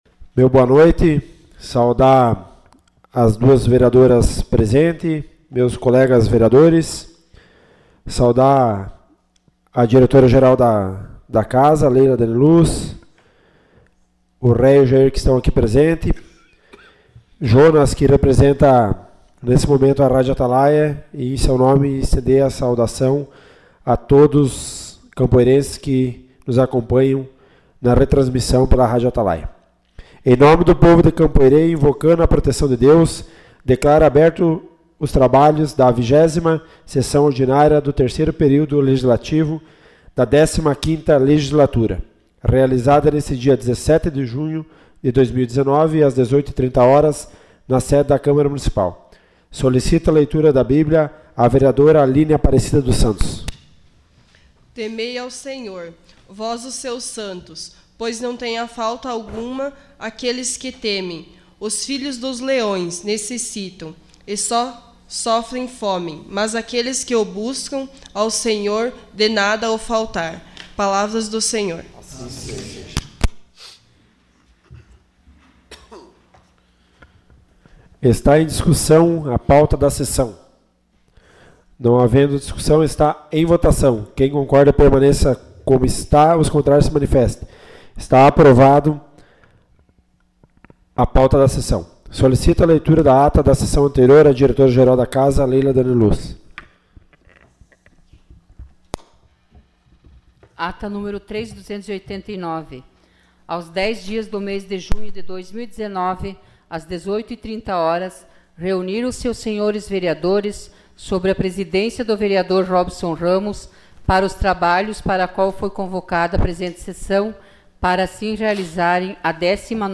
Sessão Ordinária 17 de junho de 2019.